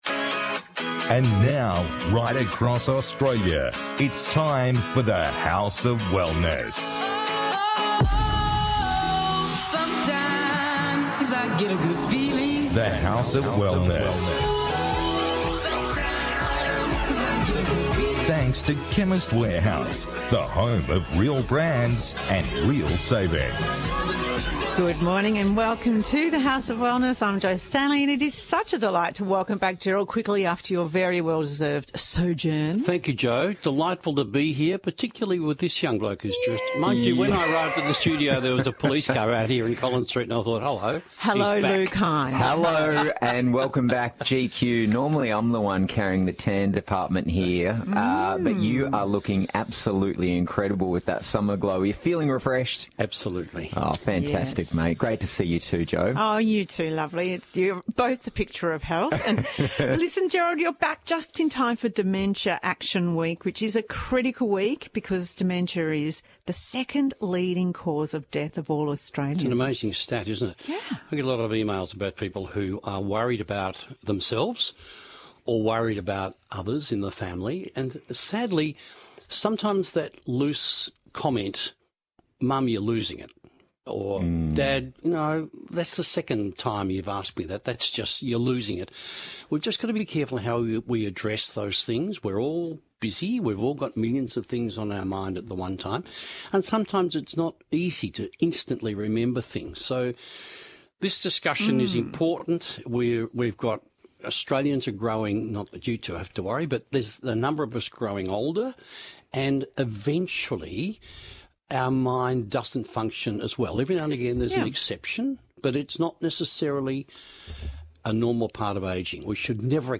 The House of Wellness Radio - Full Show 17th September 2023
On this week’s The House of Wellness radio show: